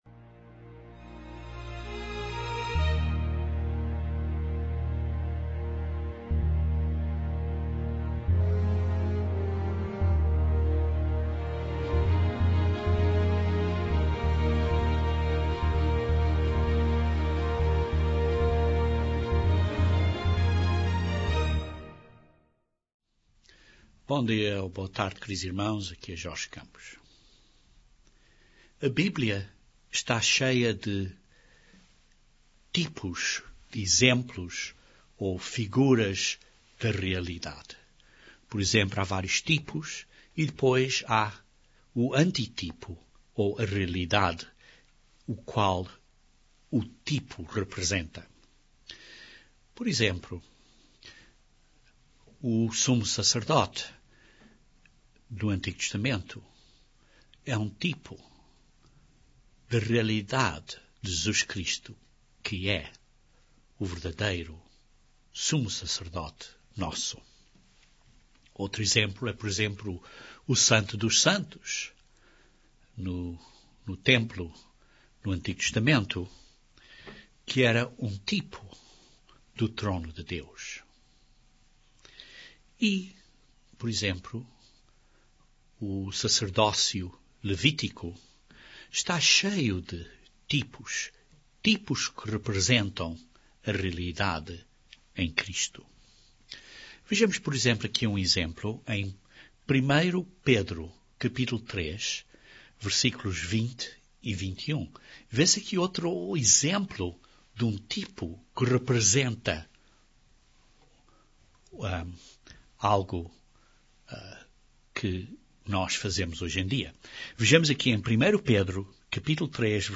Nós, os verdadeiros Cristãos, circumcizos no coração, somos mandados celebrar a Páscoa do Novo Testamento. Este sermão descreve diferênças entre a administração da Páscoa do Antigo Testamento e a administração da Páscoa do Novo Testamento.